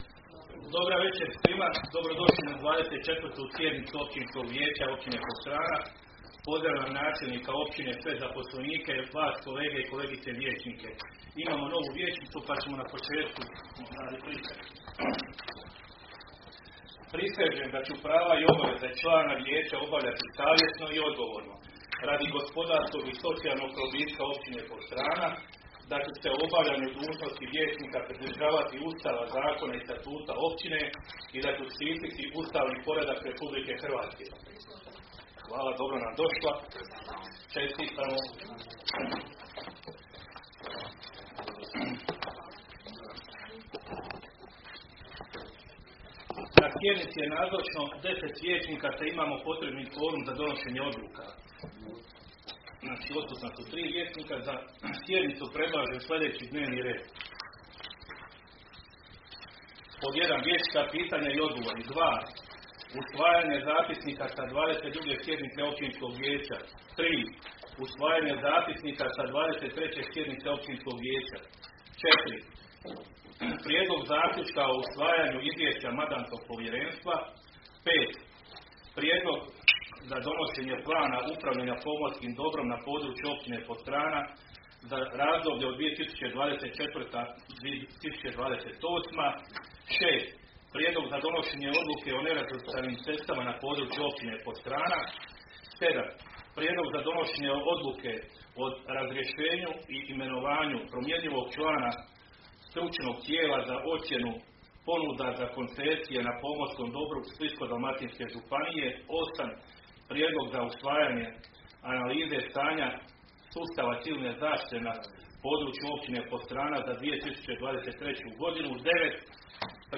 Sjednica će se održati dana 18. siječnja (četvrtak) 2024. godine u 19,00 sati u vijećnici Općine Podstrana.